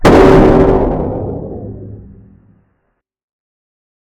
explosion1.ogg